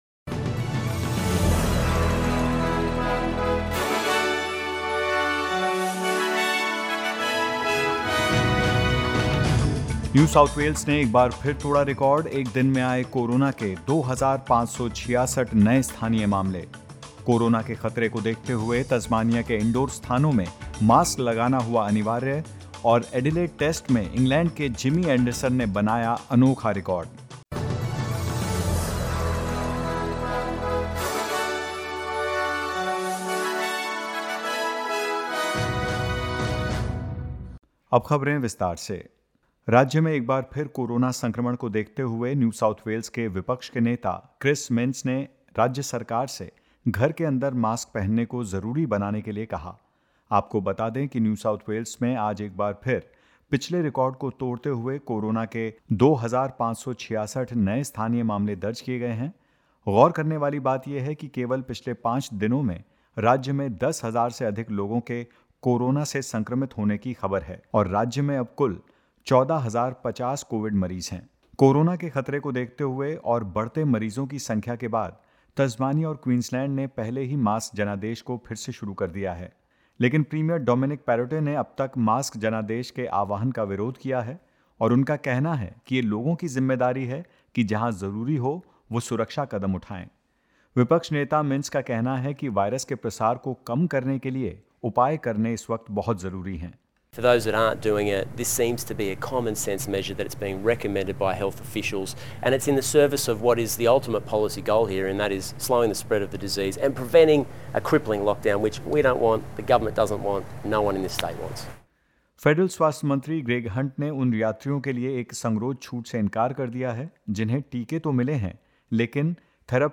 In this latest SBS Hindi news bulletin: A sixth child has died following the jumping castle tragedy in Tasmania; Victoria's mental health workforce is set to be expanded and more.